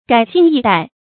改姓易代 注音： ㄍㄞˇ ㄒㄧㄥˋ ㄧˋ ㄉㄞˋ 讀音讀法： 意思解釋： 謂朝代改換。泛指政權更替。